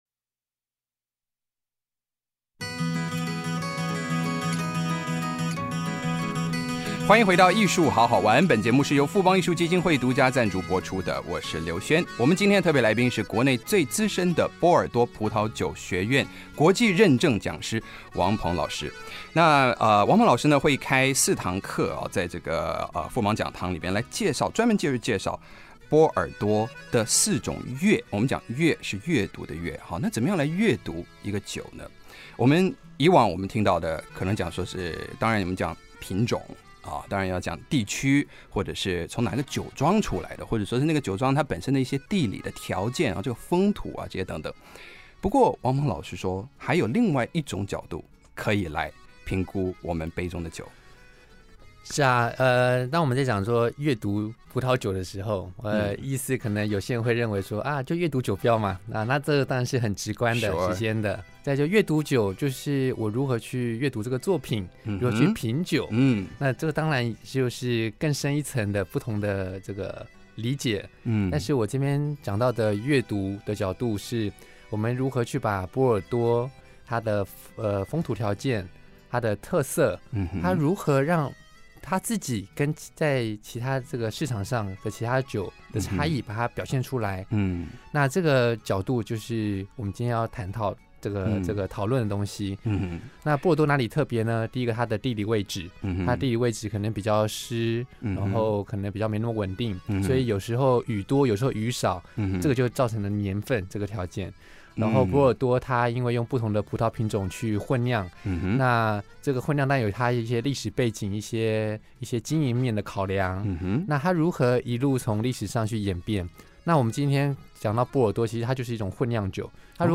【飛碟電台〈藝術好好玩〉訪談
劉軒主持